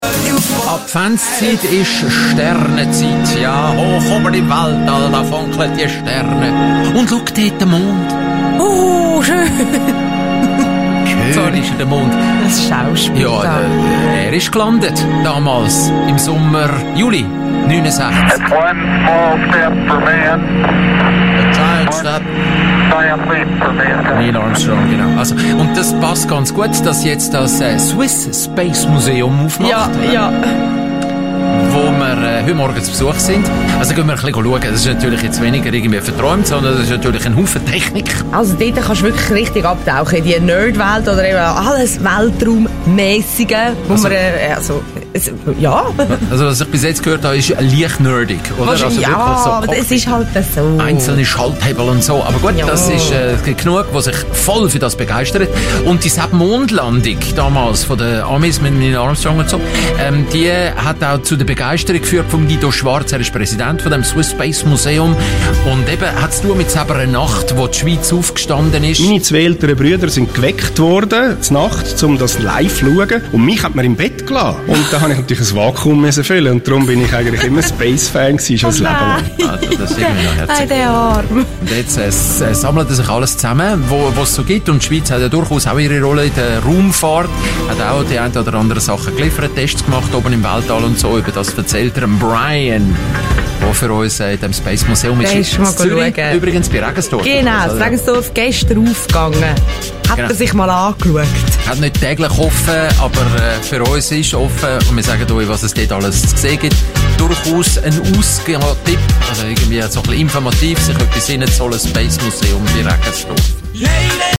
Radiobeitrag Teil 1